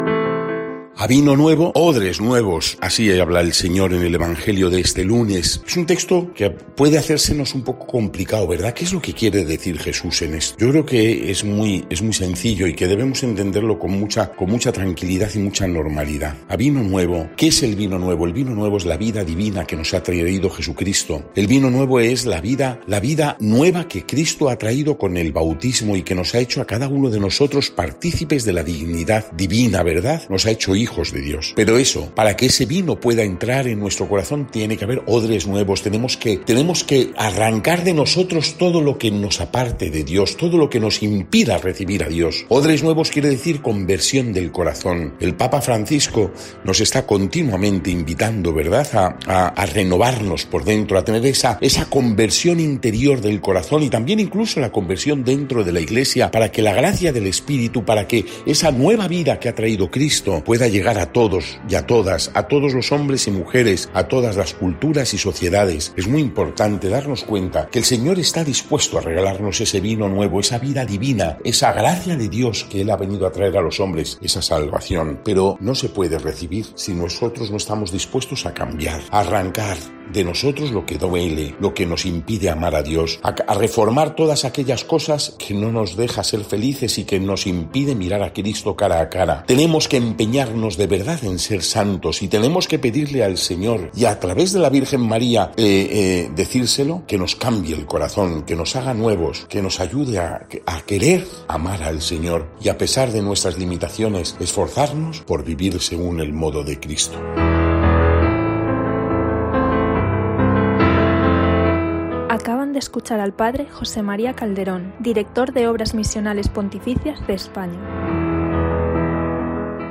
Evangelio del día